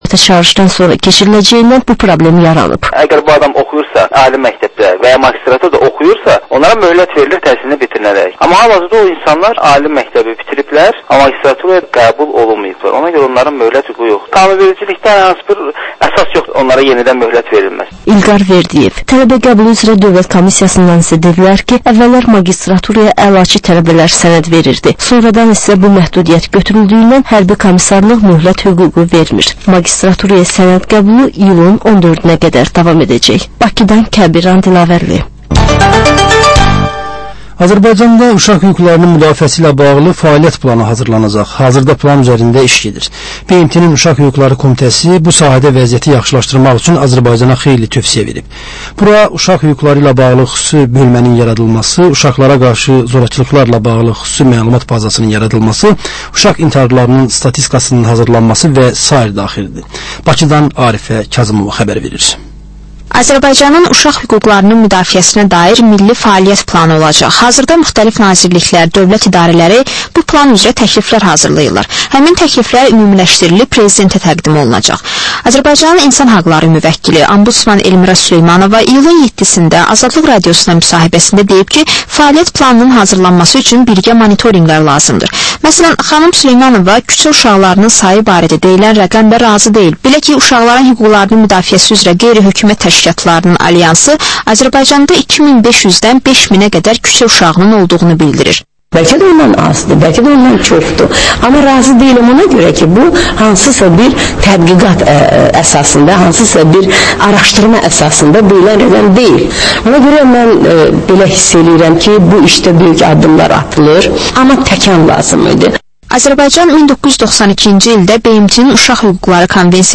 Reportajç müsahibə, təhlil